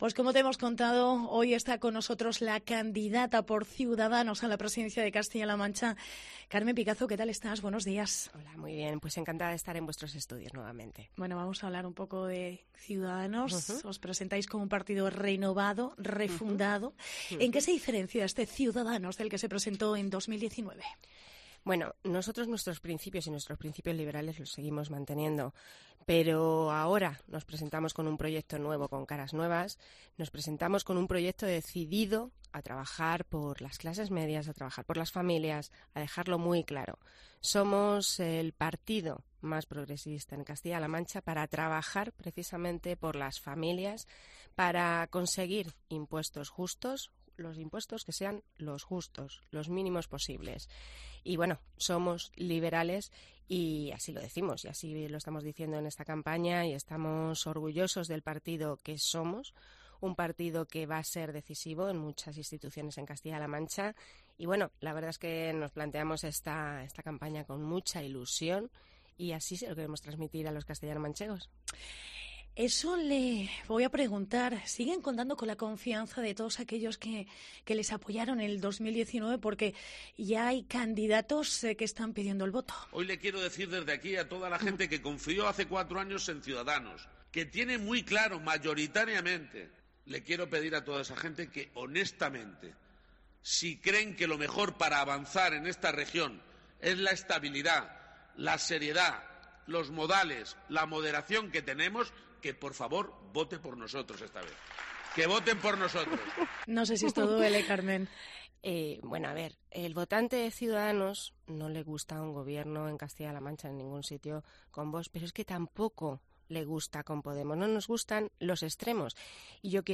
Hoy visita Herrera en COPE Castilla-la Mancha, Carmen Picazo, candidata por Ciudadanos a la presidencia de la región. (PINCHA AQUÍ PARA ESCUCHAR LA ENTREVISTA)